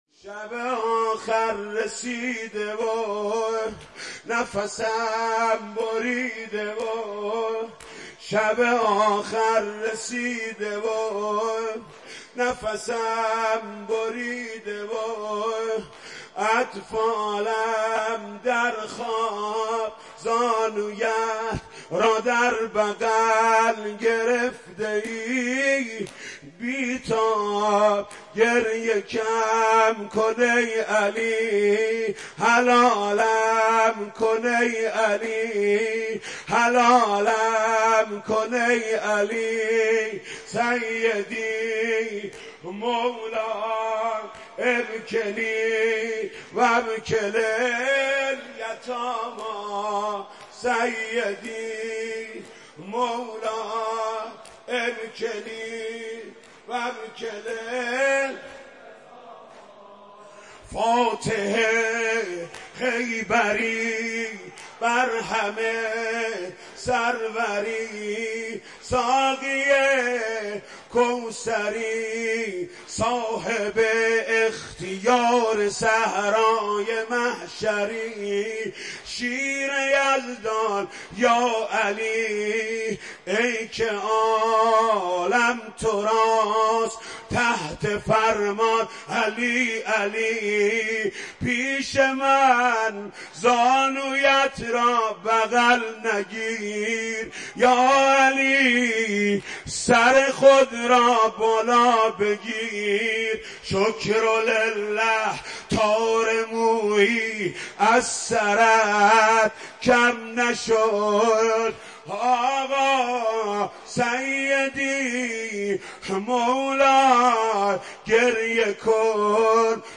مداحی صوتی
نوحه جدید